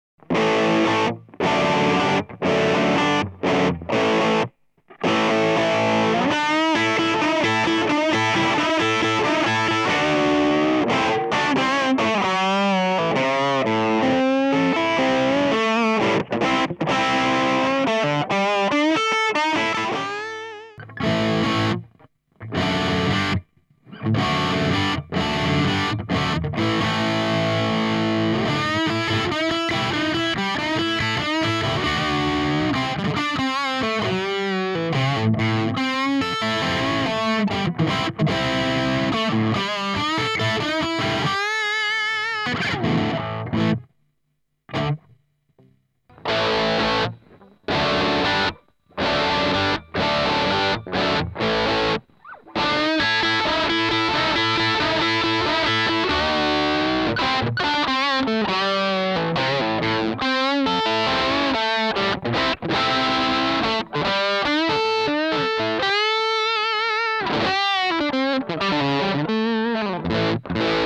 0:00 - 0:20 : IR de base dans le fractal
0:21 - 0:30 : Filtre du laney auquel j'enleve les basse et aigus via un eq de l'axe fx parce que y'a que le medium qui m'interesse dedans
0:30 - 0:37 : de nouveau le IR seul
0:38 - 0:42: IR + filtre (le filtre est un chouilla surmixé je pense, mais bon tu comprends le principe quoi)
0:49 - 1:05 : de nouveau IR+ filtre
1:05 - 1:20 : que l'IR seul auquel je monte les mediums pour essayer d'avoir le meme effet et c'est tres bof, enfin j'aime pas
apres c'est tres perfectible, mais le filtre m'emmene, à mes gouts en tout cas, ce qui me manque dans les medium ce coté "chaud" woody" (lol le terme) d'un baffle que je retrouve jamais dans les IR
c'est super intéressant. le medium du filtre donne vraiment du punch et effectivement ce coté "chaud". l'IR tout seul à la fin ça sonne nettement plus agressif dans les mids.
cabsim.mp3